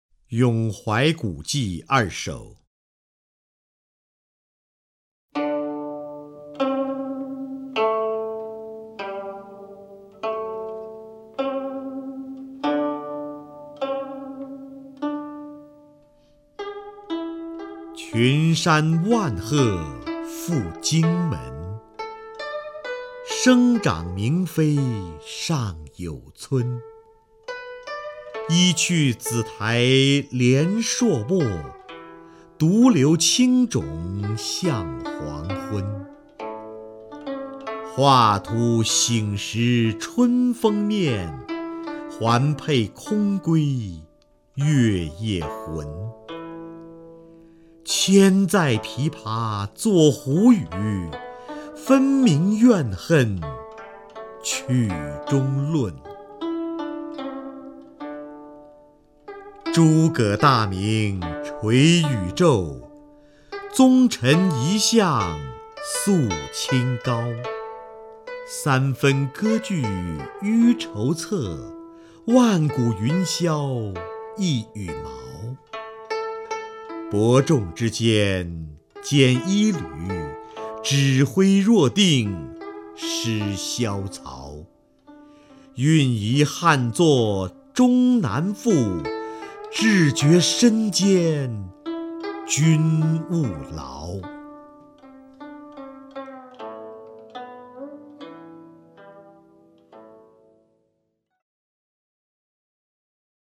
瞿弦和朗诵：《咏怀古迹·其三、其五》(（唐）杜甫)
名家朗诵欣赏 瞿弦和 目录